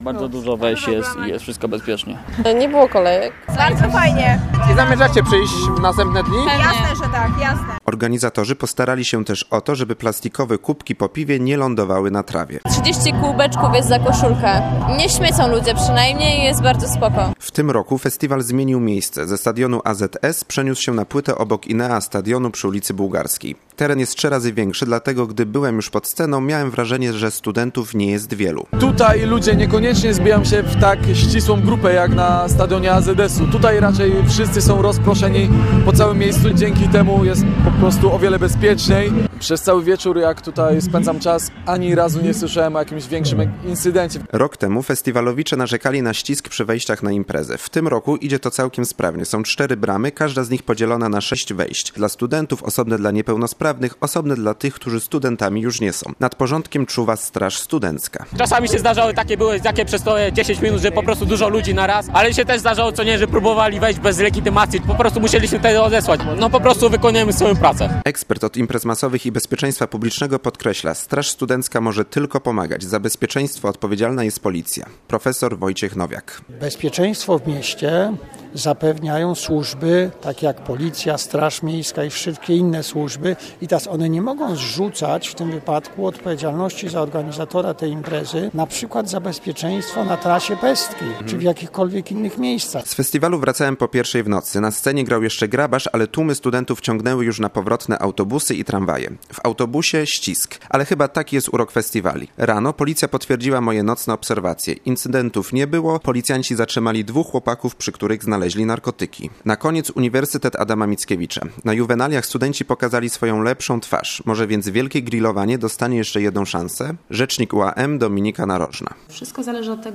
Hucznym, kolorowym pochodem rozpoczęły się poznańskie Juwenalia 2014. W tym roku zmieniło się i miejsce i trasa pochodu.